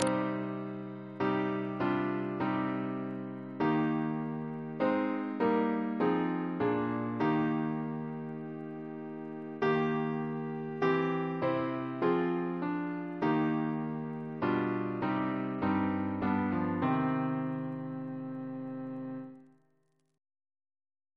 CCP: Chant sampler
Double chant in C minor Composer: William Cross (1777-1825) Reference psalters: PP/SNCB: 54 105